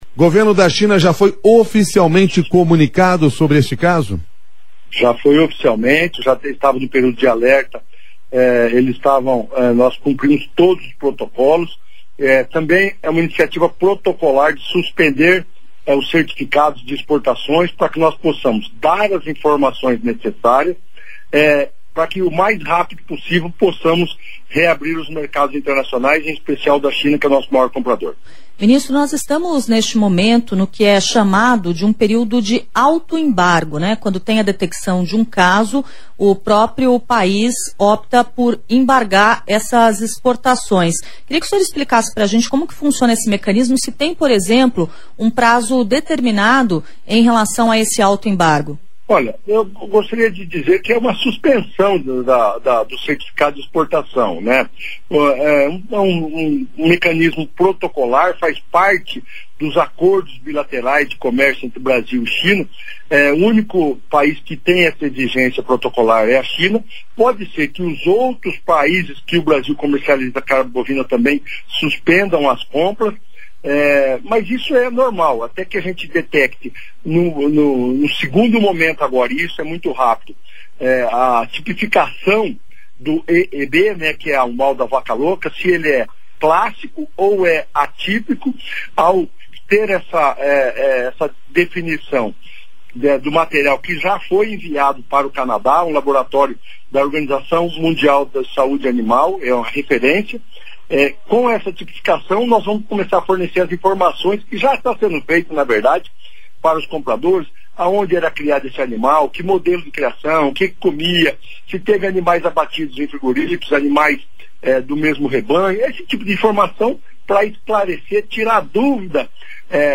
Player Ouça CARLOS FÁVARO, MINISTRO DA AGRICULTURA